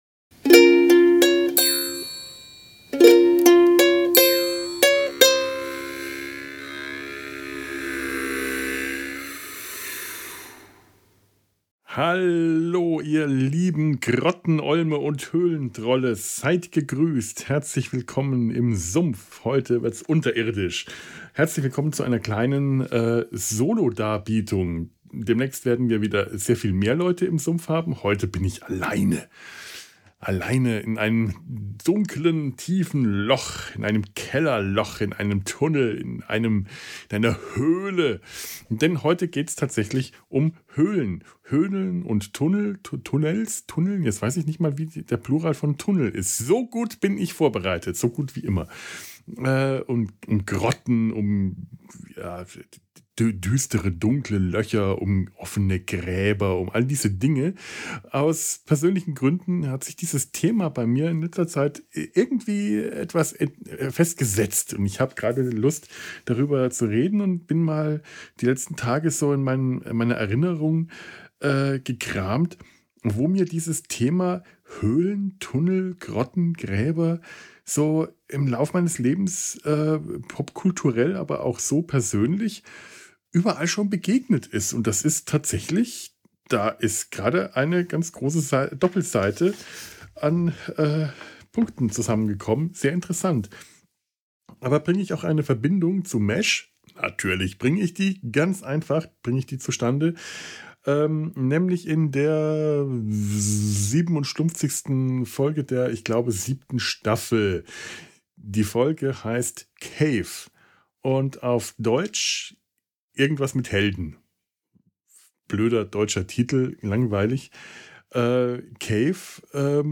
Aus Gründen nehme ich eine kleine Solo-Plauderei auf, die mir schon seit einigen Tagen zum Thema Höhlen, Tunnel, Grotten, Gräbern und anderen unterirdischen Begebenheiten im Kopf herum spukt...